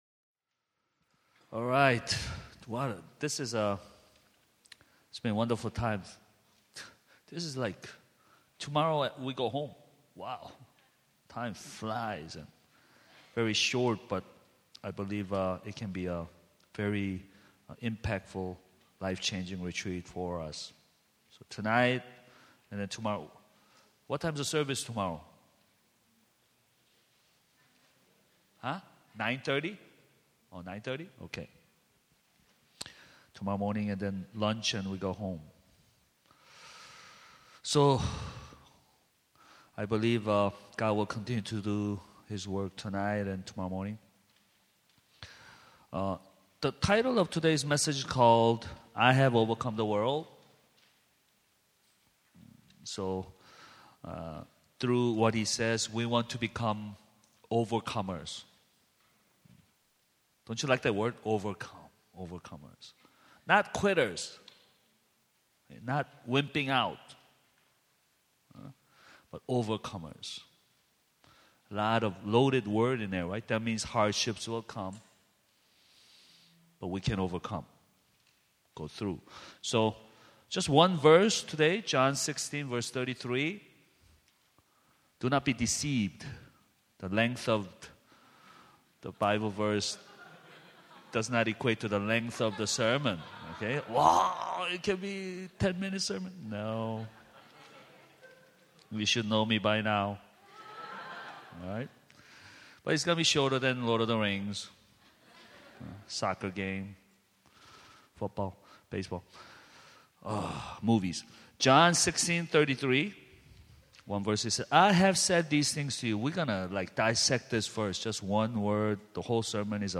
We apologize; audio cuts out midway through the sermon due to a power outage.
Download Audio Subscribe to Podcast Audio Sermon Summary We apologize; audio cuts out midway through the sermon due to a power outage.